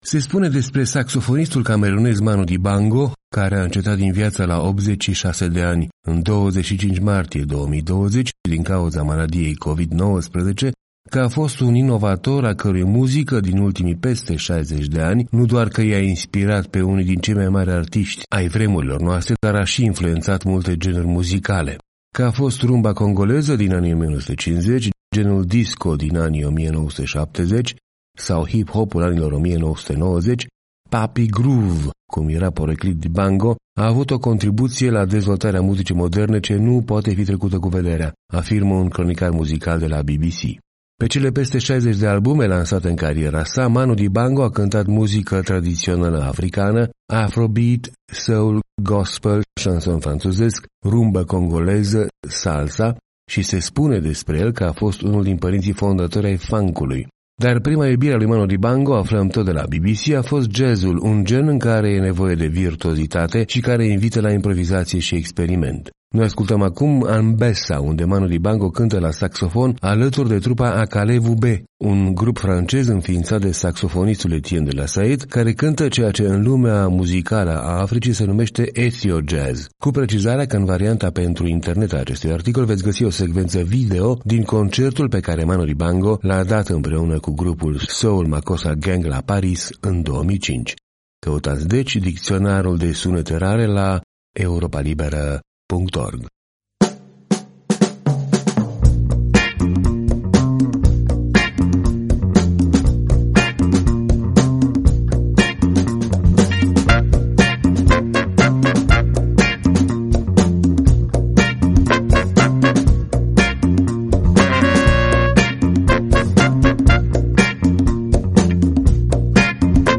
Noi ascultăm acum „Anbessa”, unde Manu Dibango cântă la saxofon alături de trupa Akalé Wubé